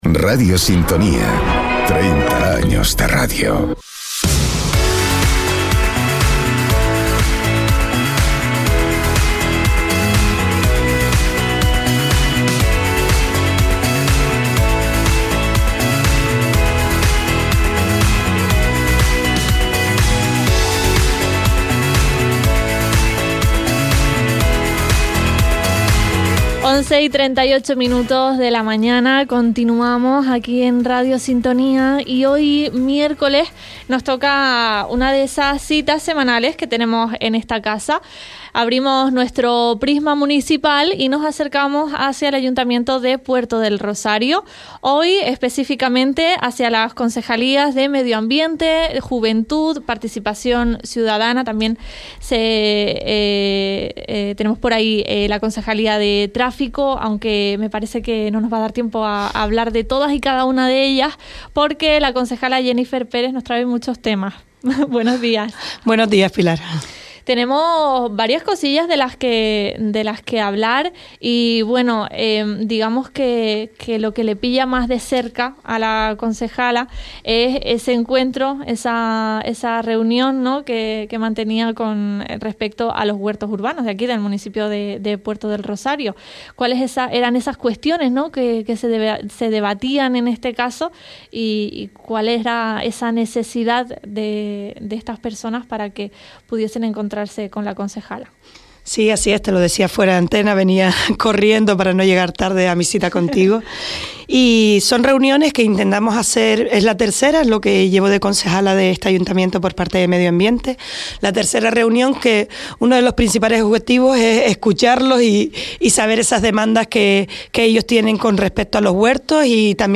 Compartimos el espacio Prisma Municipal con Jenifer Pérez, concejala de Participación Ciudadana, Medio Ambiente y Juventud del Ayuntamiento de Puerto del Rosario